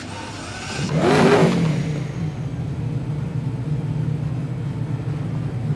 start.wav